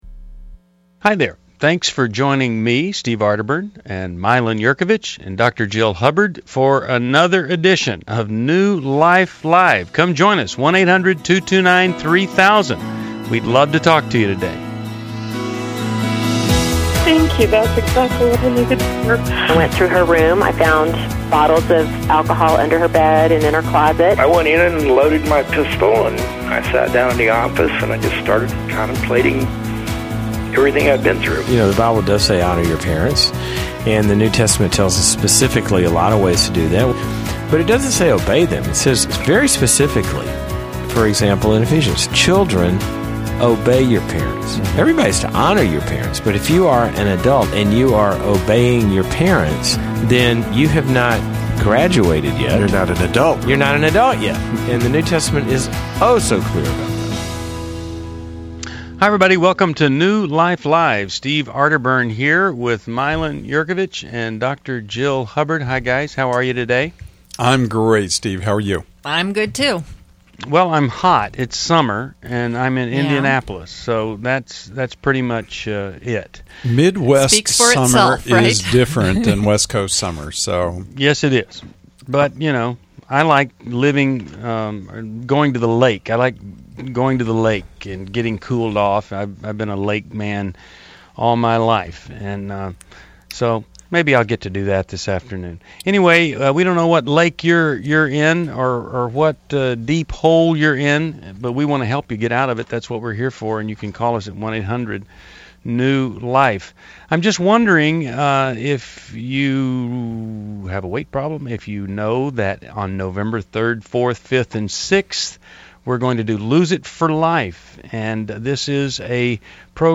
Explore relationship challenges on New Life Live: July 28, 2011, as hosts tackle topics like sexual integrity, grief, and parenting through caller insights.